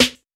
JJSnares (37).wav